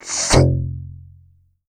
TSOOK2XM.wav